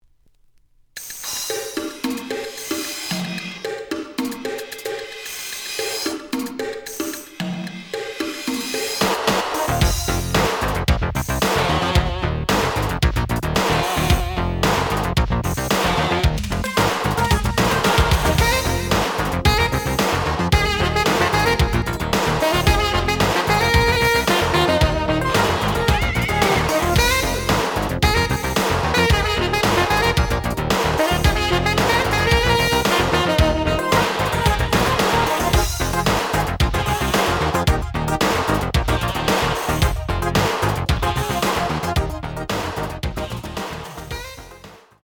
試聴は実際のレコードから録音しています。
The audio sample is recorded from the actual item.
●Genre: Disco